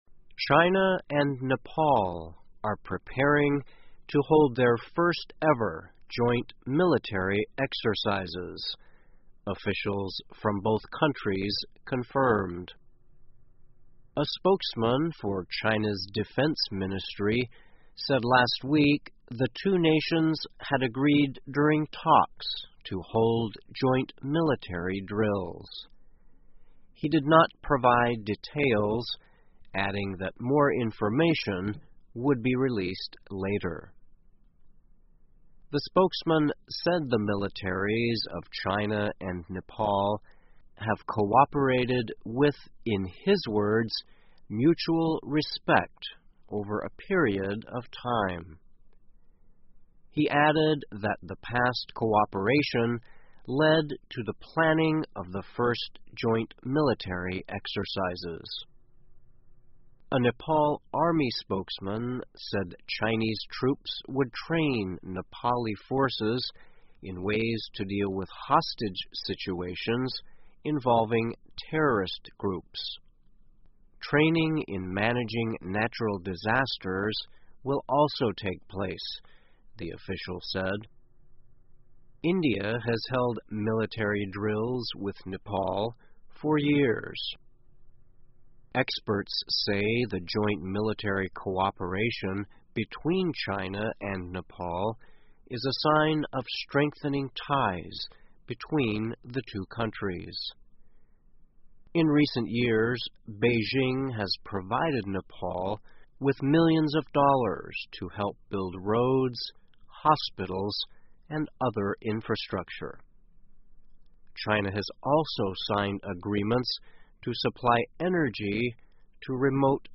VOA慢速英语207 中国尼泊尔将于2017年举行联合军演 听力文件下载—在线英语听力室